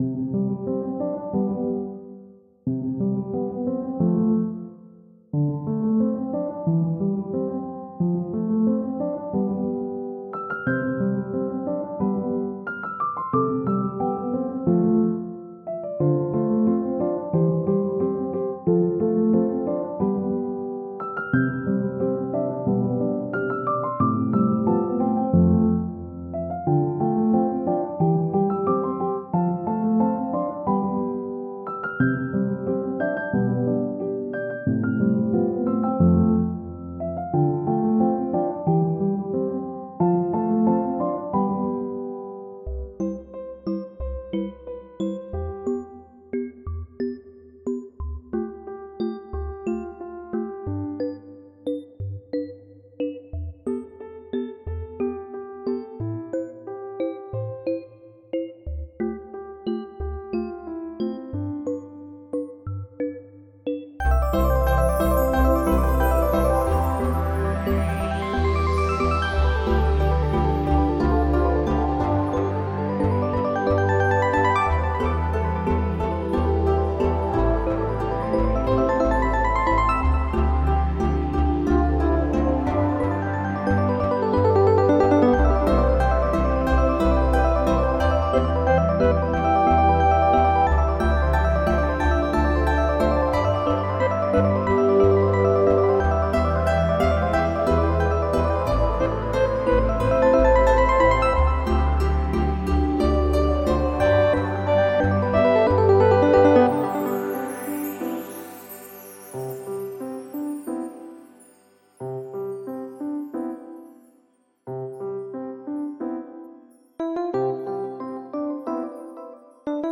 i am a huge sucker for them wooshy tekken sounds